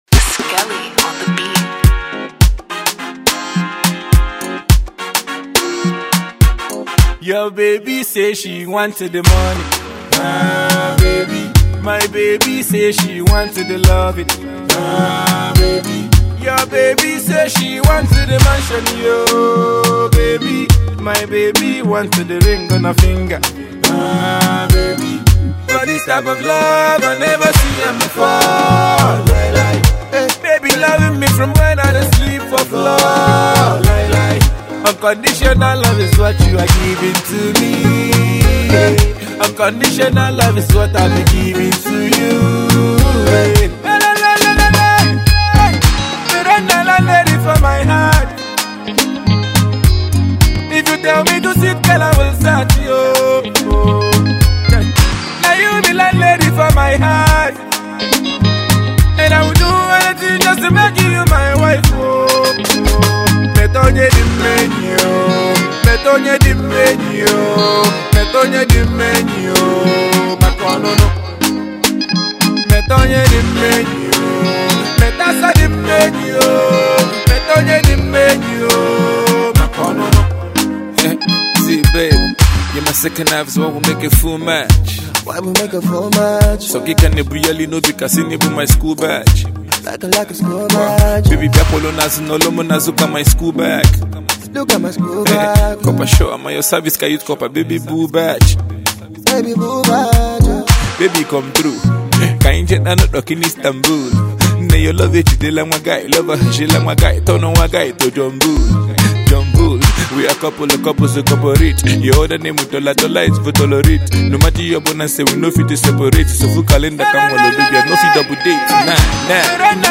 Highly rated Igbo-spitting rapper